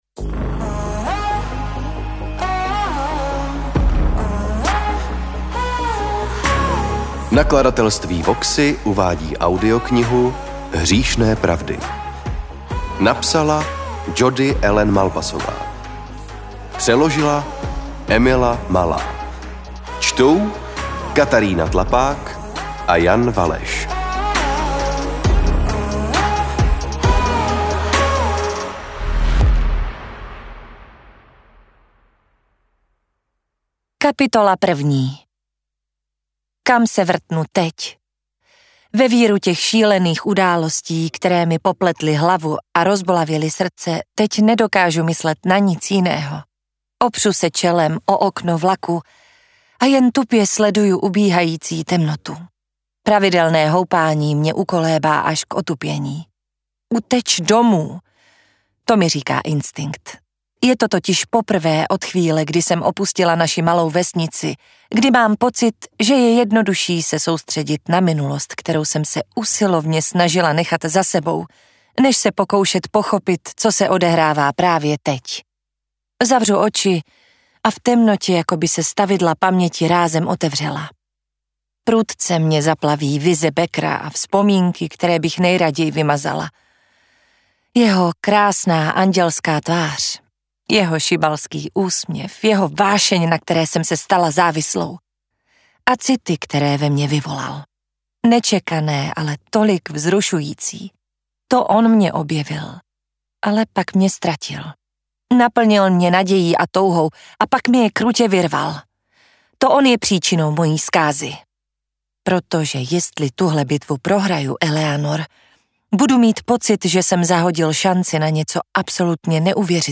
AudioKniha ke stažení, 51 x mp3, délka 17 hod. 24 min., velikost 951,6 MB, česky